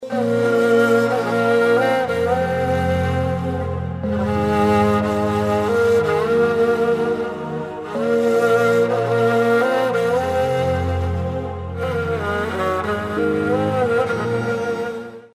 رینگتون احساسی و محزون
(بی کلام)